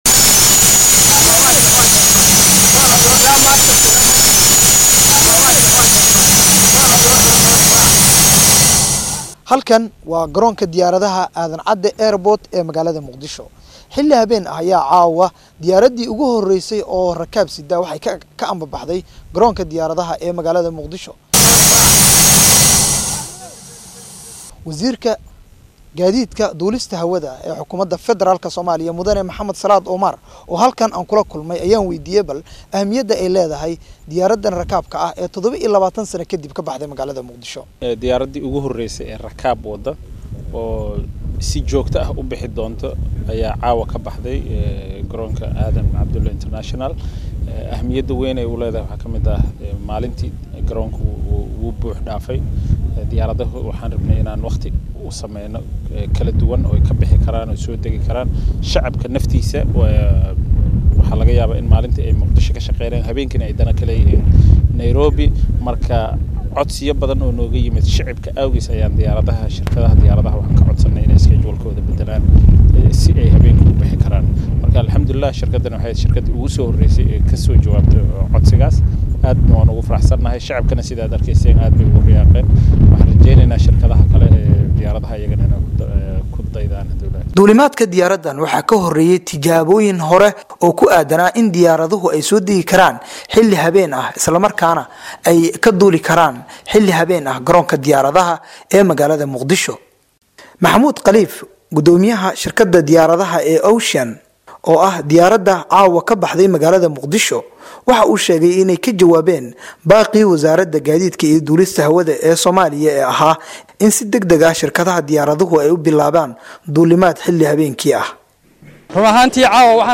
Dhageyso: Warbixin ku saabsan shaqada Garoonka diyaaradaha ee Muqdisho Aadan Cade